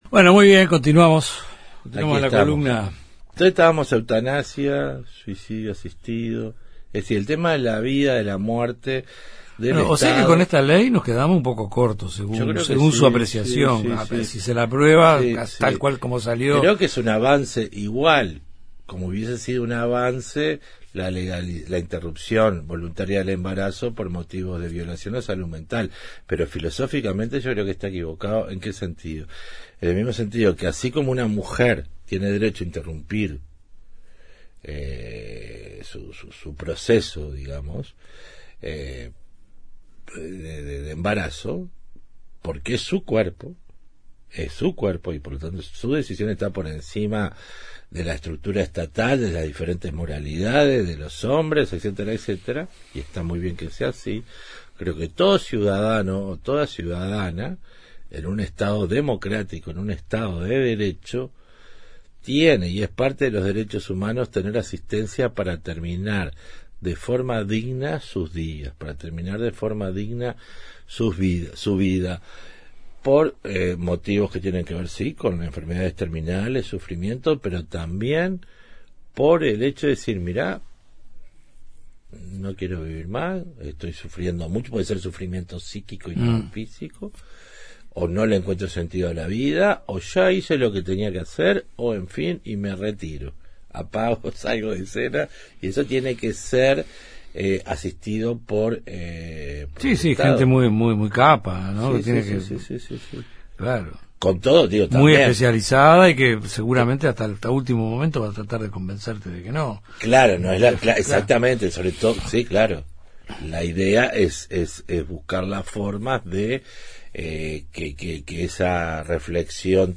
Columna del antropólogo